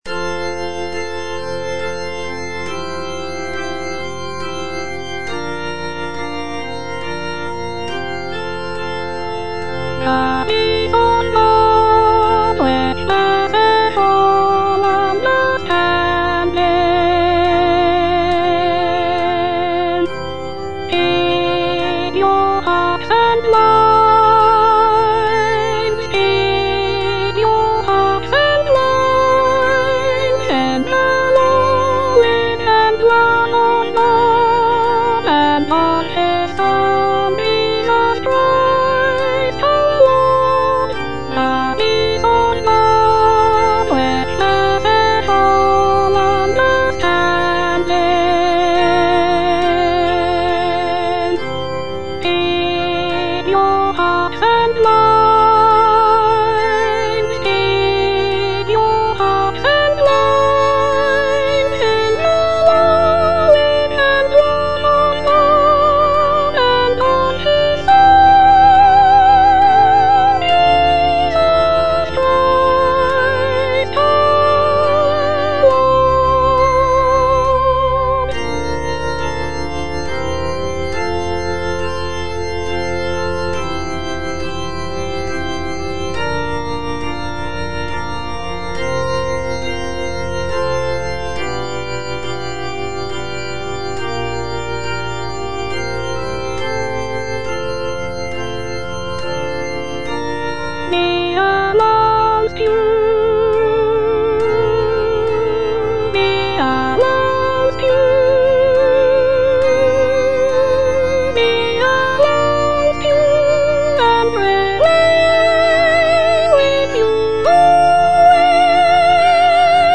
Soprano (Voice with metronome)